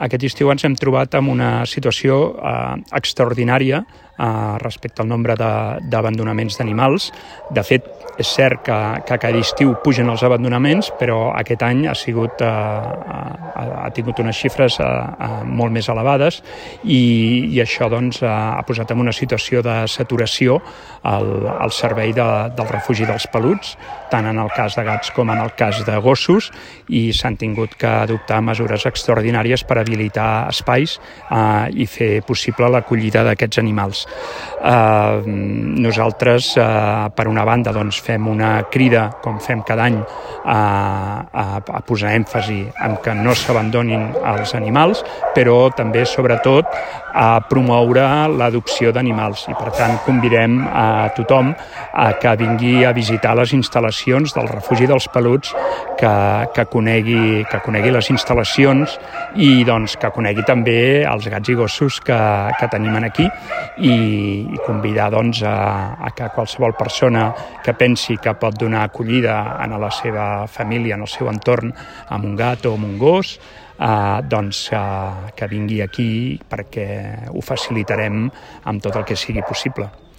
tall-de-veu-del-regidor-jaume-rutllant